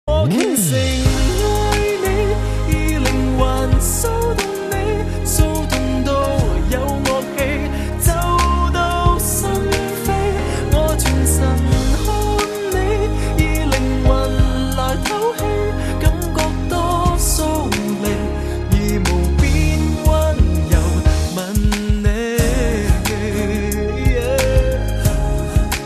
M4R铃声, MP3铃声, 华语歌曲 51 首发日期：2018-05-15 12:14 星期二